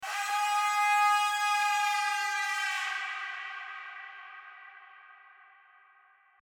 Psychedelic Trance - Elephant Horn 150bpm Bouton sonore